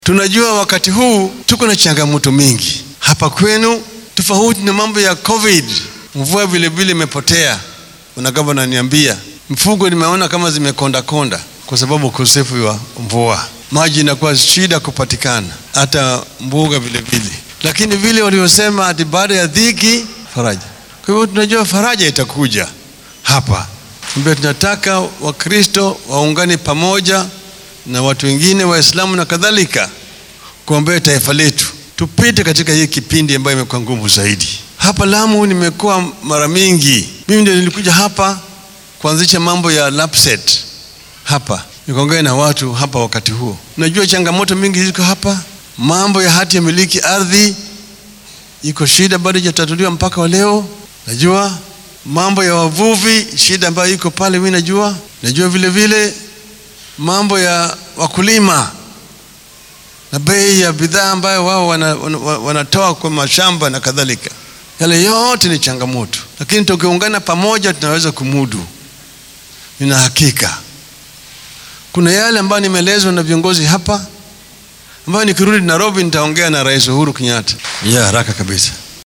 Xilli uu hadal kooban ka jeediyay kaniisadda Full Gospel ee Mpeketoni ayuu ra’iisul wasaarihii hore ee dalka soo hadal qaaday caqabadaha wadanka ka jiro ee ay abaartu ka mid tahay.